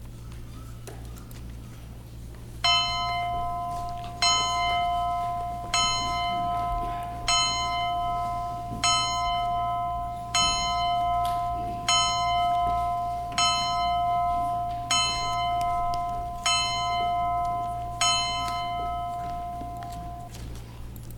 BellToll.mp3